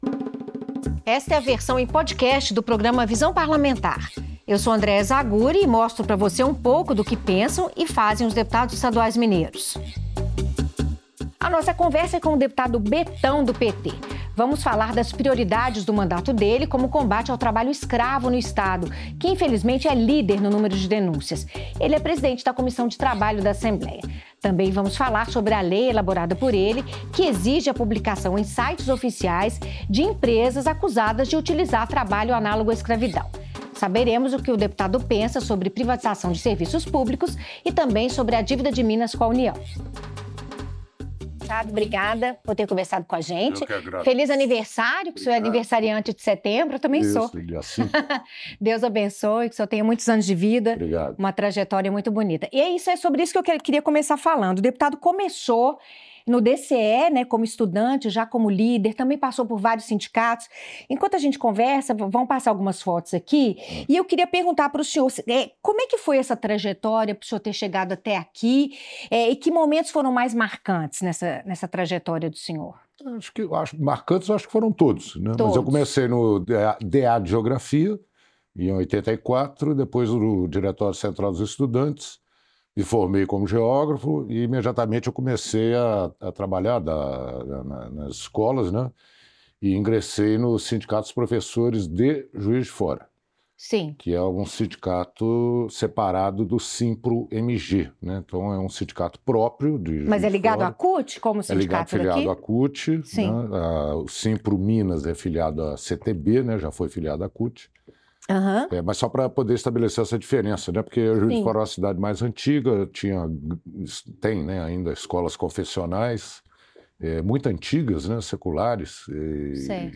Em entrevista ao programa Visão Parlamentar, o deputado Betão (PT) relembra sua trajetória política, que começou no Sindicato dos Professores de Juiz de Fora (Sinpro-JF). O parlamentar elogia a criação do piso nacional da educação básica, definido pela Lei Federal 11.738, de 2008, mas acrescenta que a maioria das prefeituras e estados brasileiros ainda não cumprem a legislação. O deputado também fala sobre a existência de trabalho análogo à escravidão em diferentes setores da economia mineira.